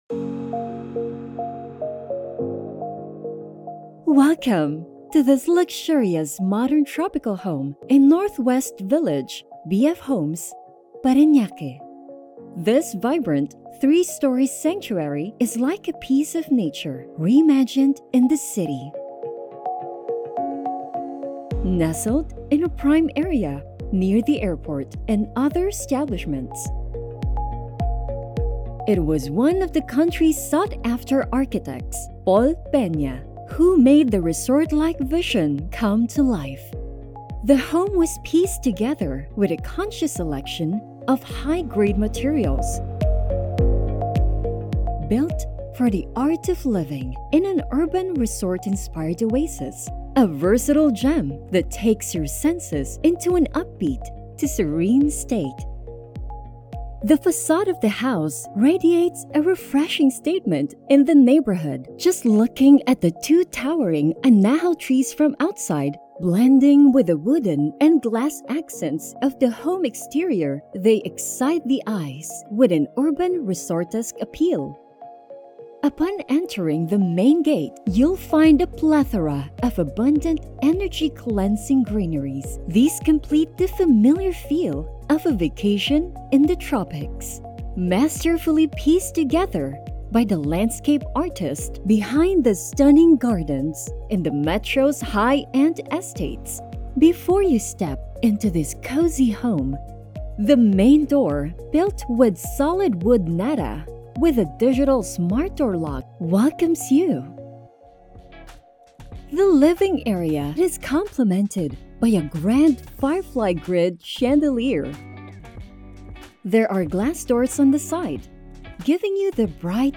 Female
My voice type is deep, authoritative, adult, teenager, and corporate.
Explainer Videos